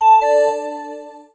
LH notificate (V2).wav